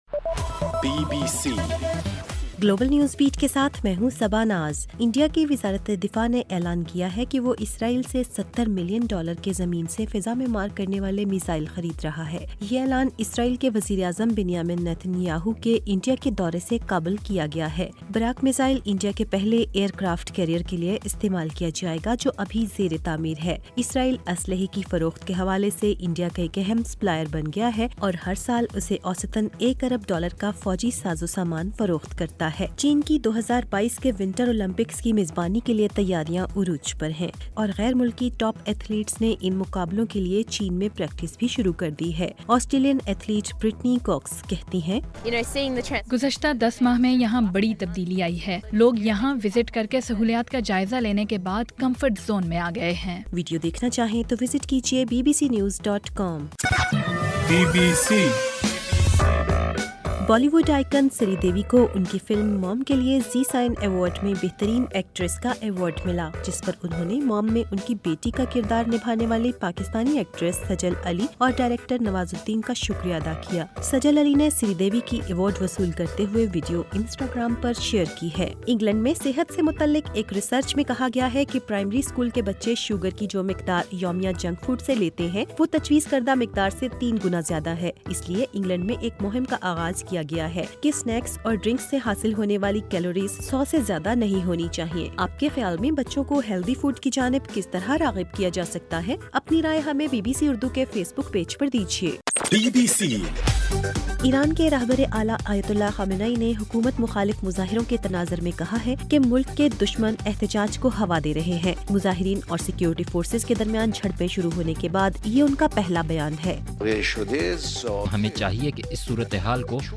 گلوبل نیوز بیٹ بُلیٹن اُردو زبان میں رات 8 بجے سے صبح 1 بجے ہرگھنٹےکے بعد اپنا اور آواز ایف ایم ریڈیو سٹیشن کے علاوہ ٹوئٹر، فیس بُک اور آڈیو بوم پر سنئیِے